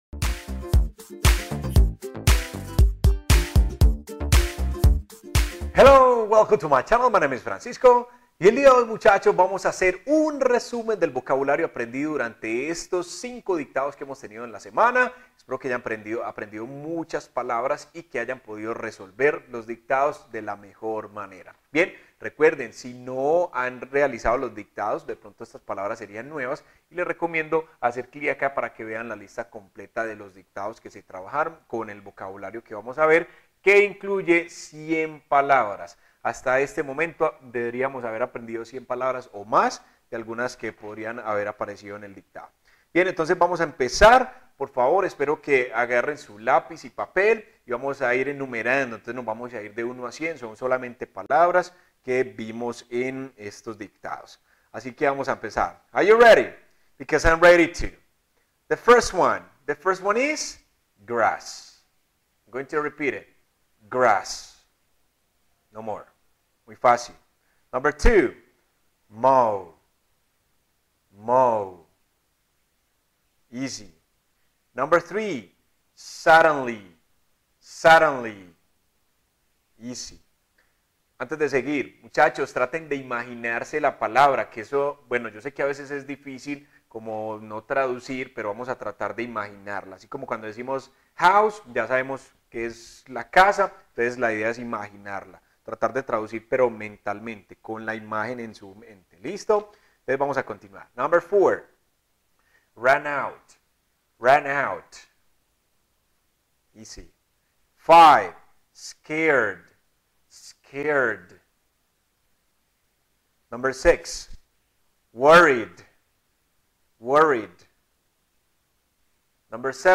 ✍ Dictado guiado en inglés con 100 palabras: expande tu vocabulario rápido y fácil